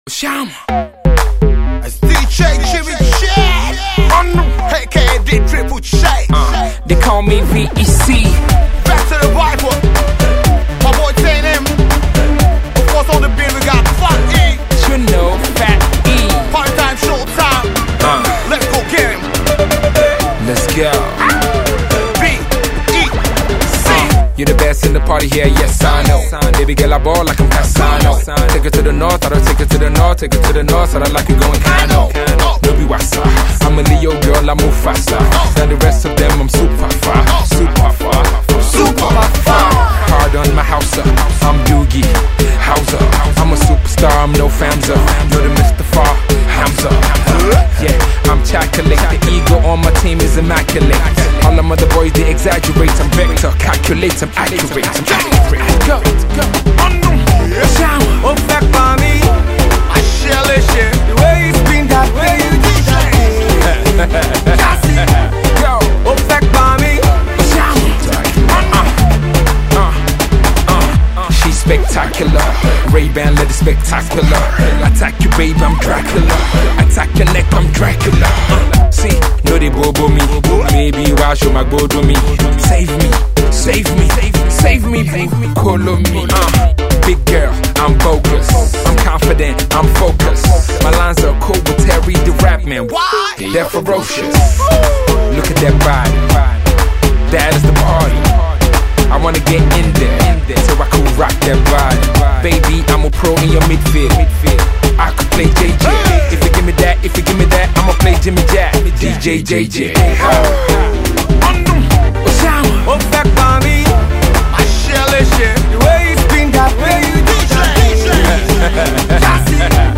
Azonto-inspired instrumentation